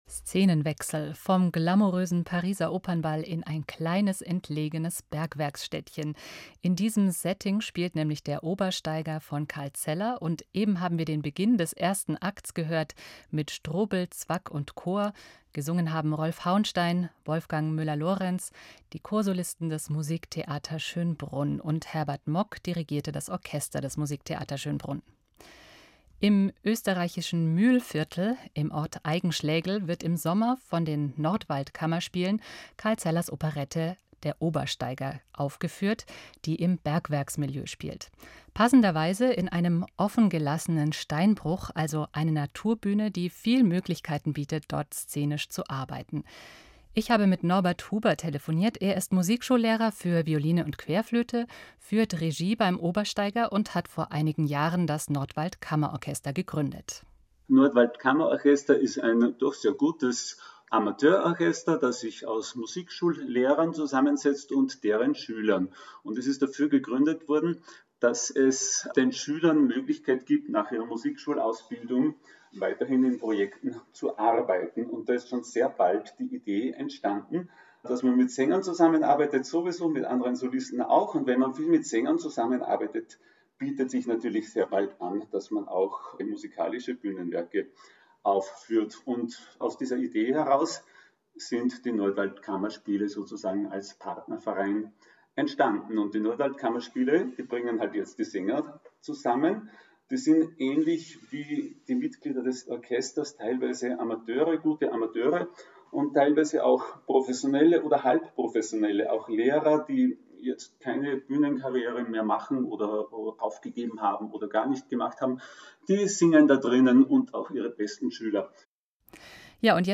Die Musikbeiträge mussten aus urheberrechtlichen Gründen herausgeschnitten werden.